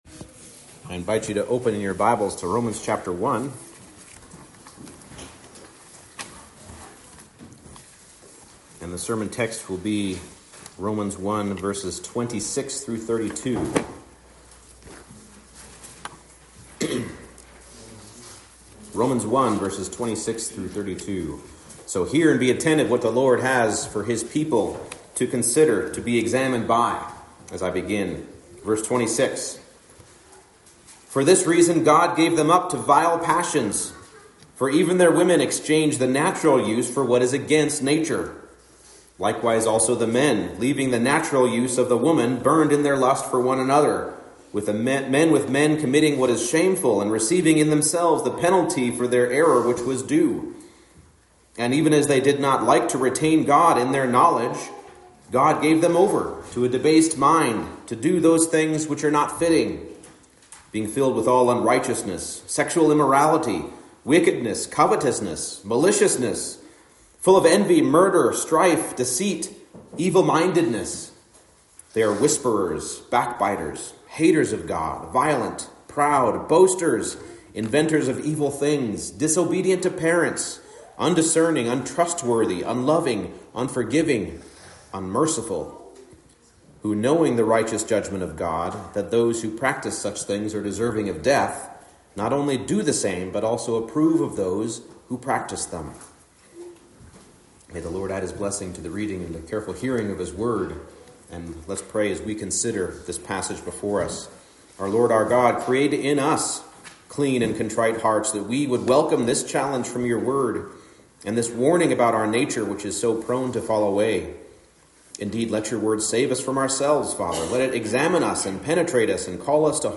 Romans 1:26-32 Service Type: Morning Service The nature of sin is that it is its own consequence.